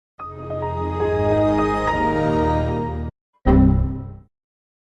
Звуки синего экрана смерти
Загрузка и синий экран смерти